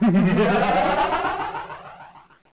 Evillaug
EVILLAUG.wav